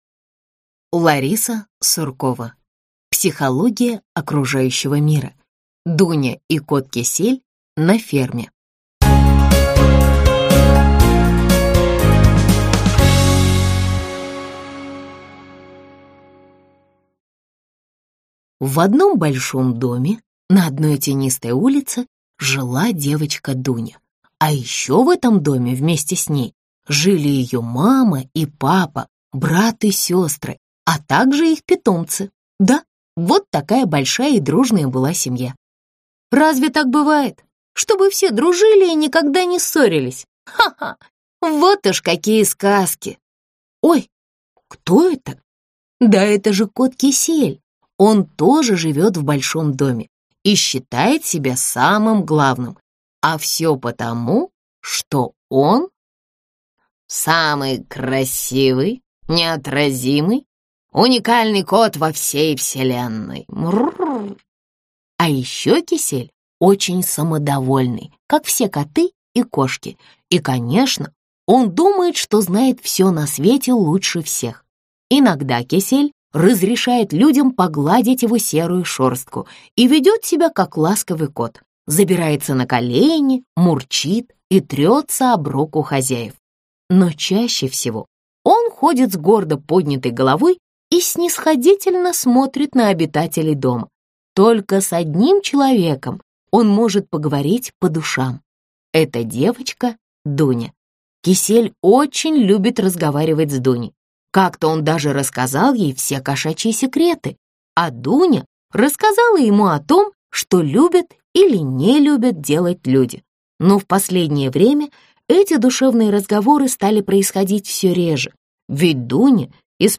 Аудиокнига Психология окружающего мира. Дуня и кот Кисель на конюшне | Библиотека аудиокниг